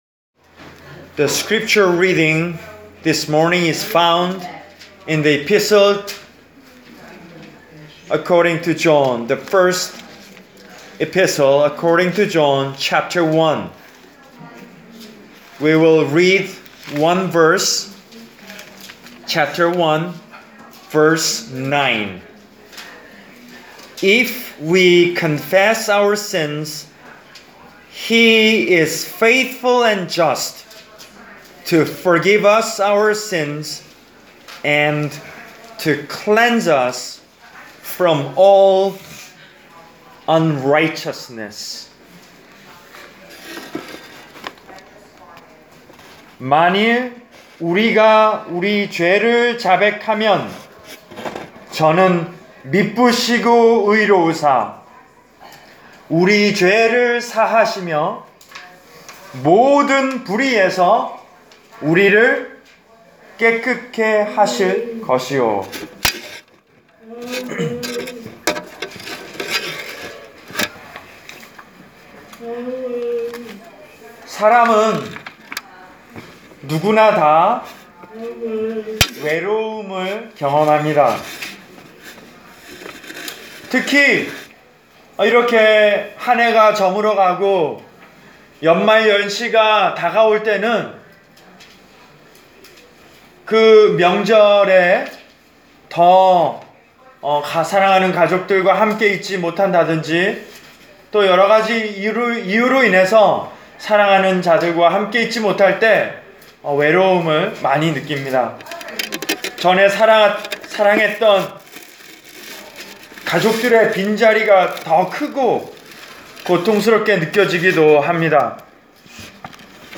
Preached for: Country Arch Care Center, Pittstown, N.J.